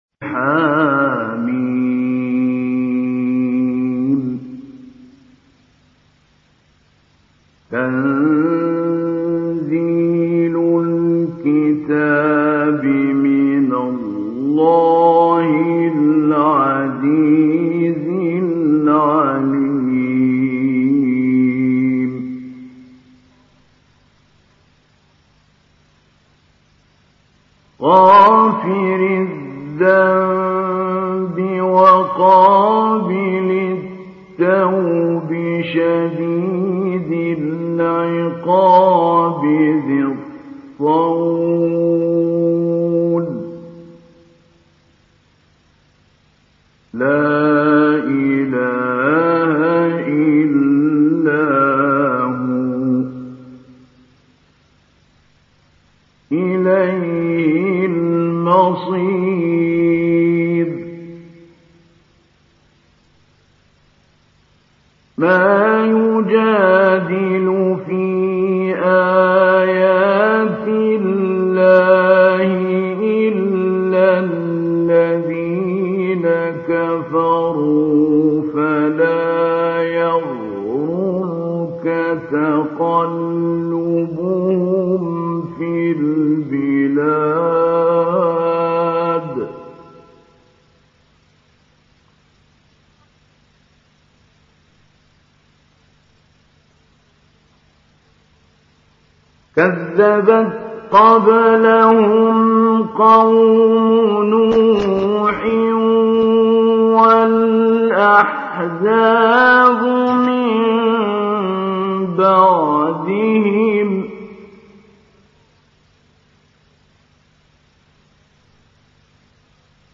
محمود علي البنا قارئ من مصر، من مواليد سنة 1926 بمحافظة المنوفية، أتمم الشيخ محمود علي البنا حفظ القرآن الكريم وهو في الحادية عشرة من عمره، ثم سعى لدراسة العلوم الشرعية حيث تلقى القراءات على يد الإمام إبراهيم بن سلام المالكي.